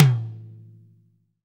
TOM TOM106.wav